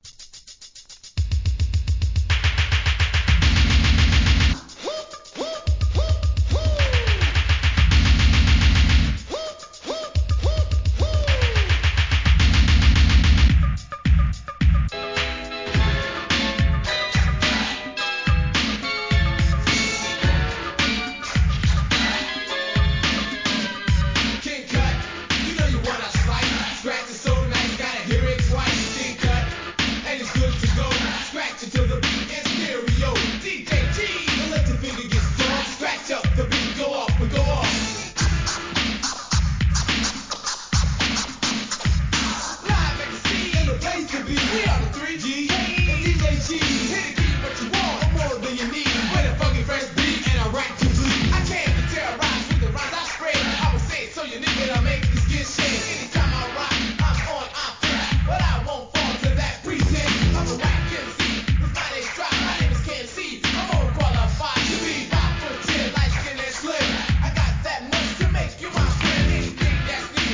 HIP HOP/R&B
EARLYでコスリ、BEATそしてRAPと勢いを感じずにはいられない1985年の人気作!!!